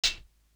Pointer Hat.wav